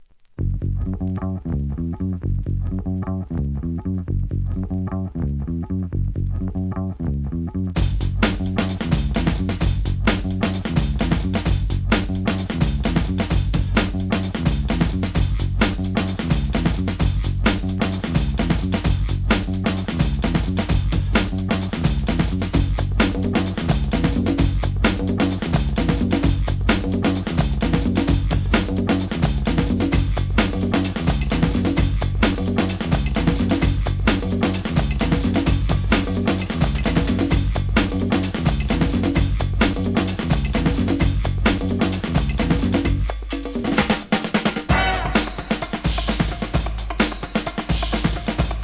HIP HOP/R&B
ブレイカー受けも間違いないブレイクビーツ